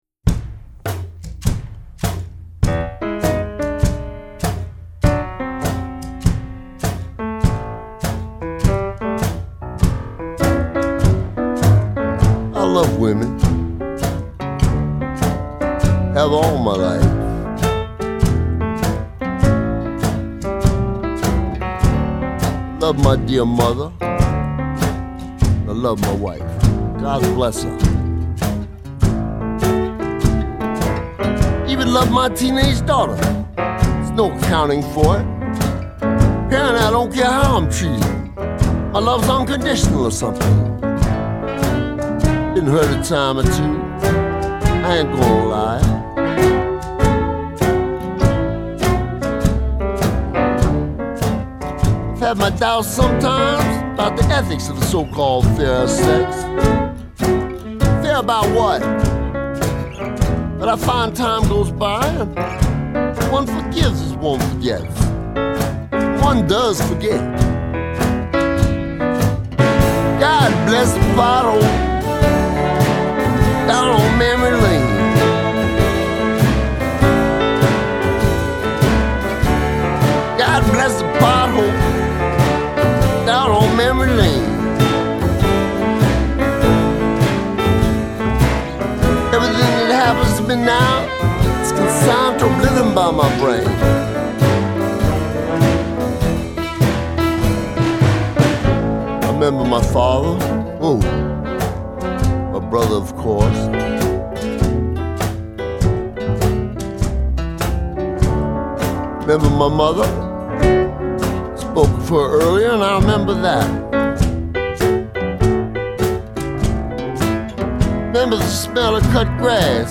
a very funny song about being unable to remember.